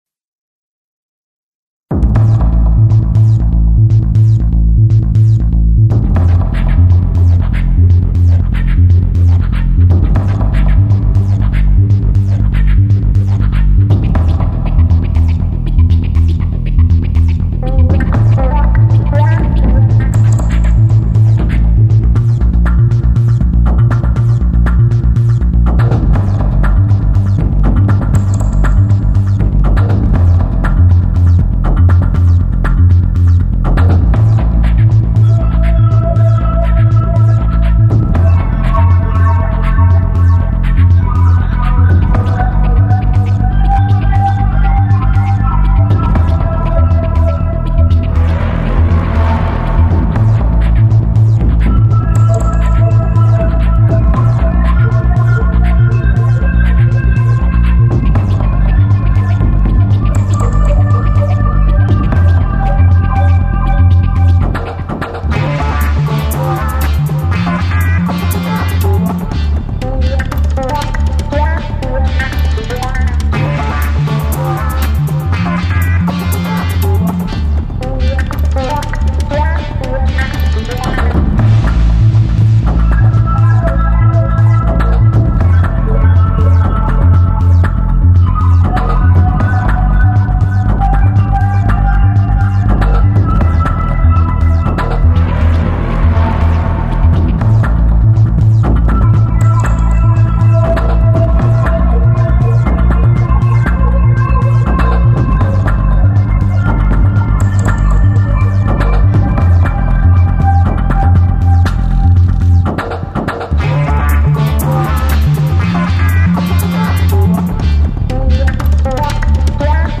SOUNDTRACK MUSIC ; ELECTRO FUNK MUSIC